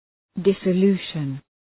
Shkrimi fonetik {,dısə’lu:ʃən}